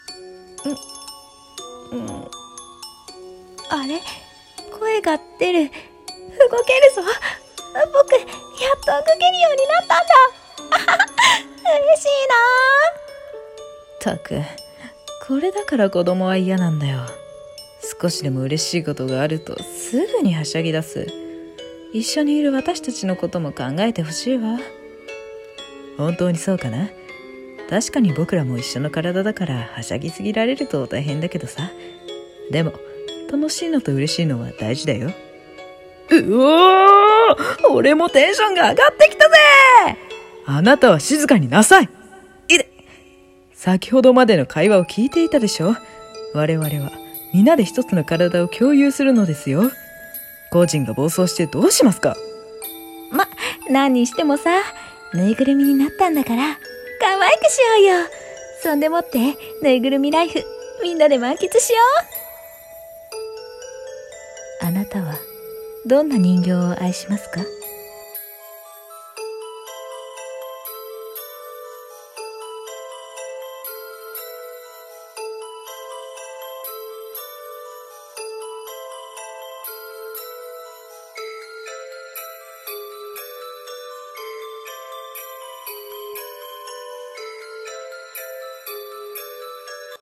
1人声劇台本 「ボクタチハイッショ」